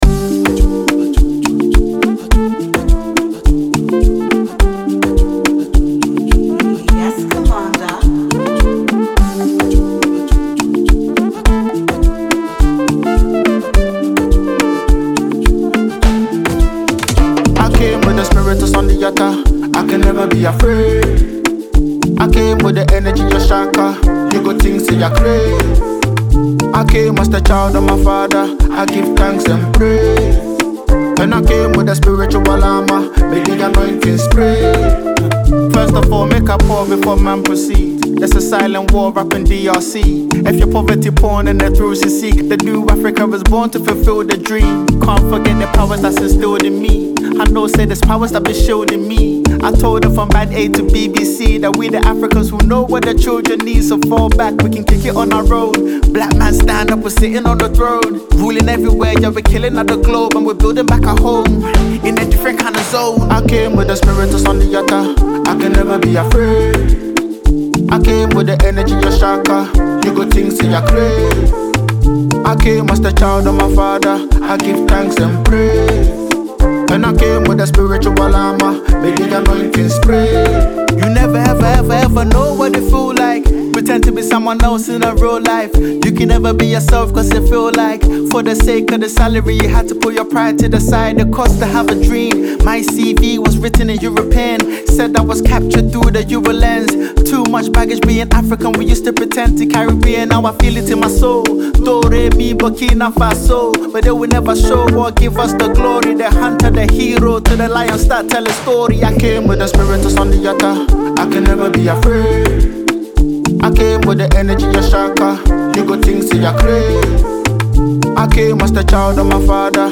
Genre: Afro-fusion / Spoken Word / Highlife